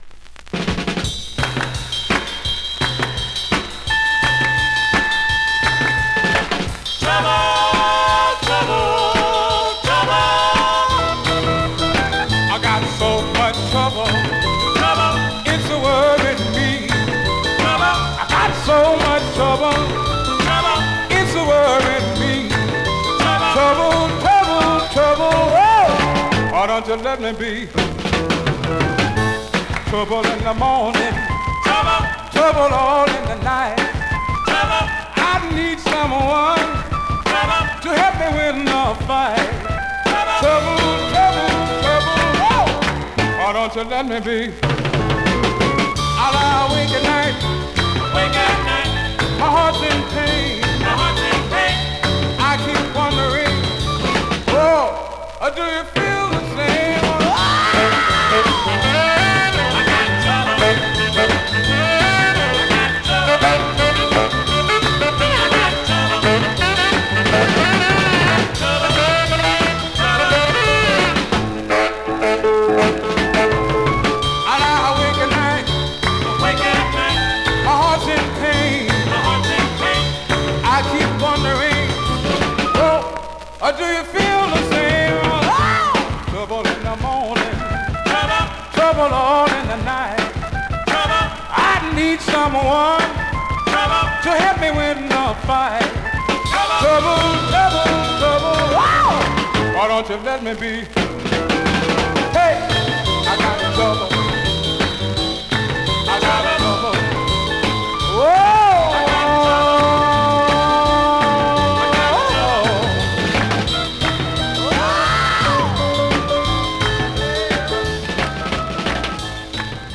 Great up-tempo Rnb / Mod dancer .
R&B, MOD, POPCORN